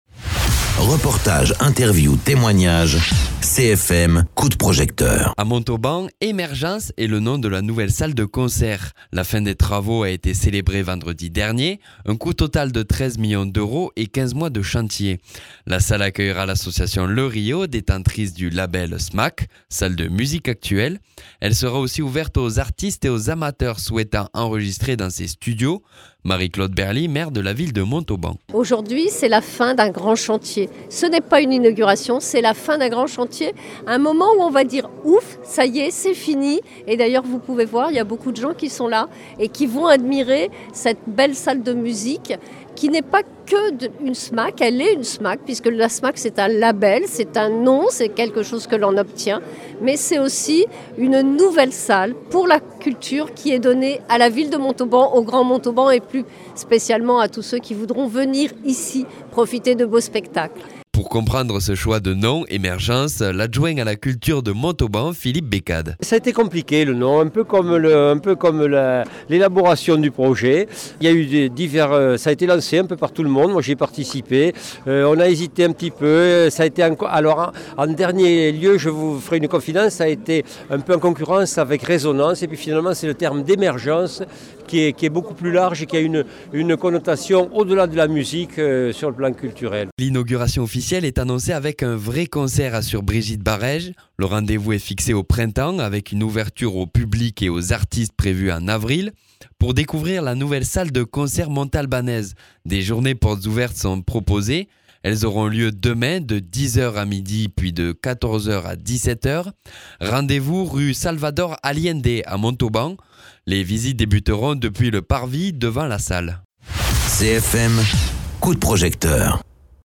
Interviews
Invité(s) : Marie-Claude Berly maire de la ville de Montauban.